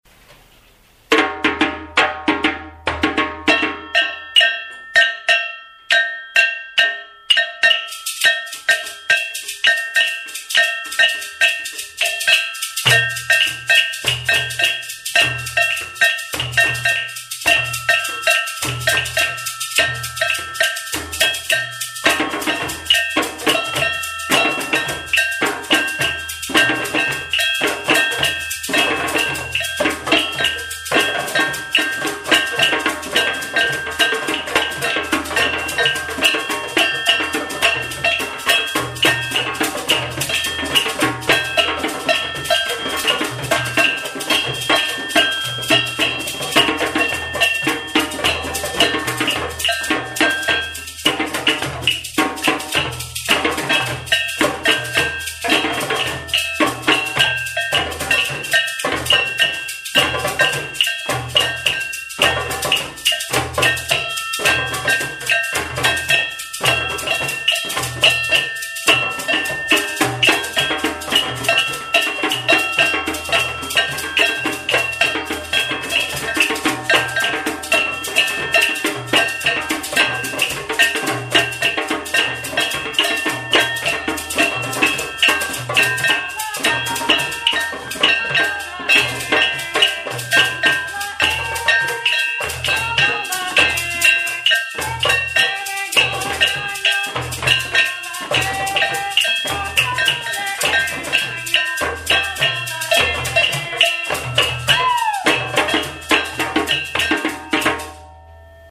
04._Channukah___Arrangement__Rhythm_and_Drumming_Ensemble__2008.wav (8.95 MB)